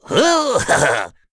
Bernheim-Vox_Happy1_z.wav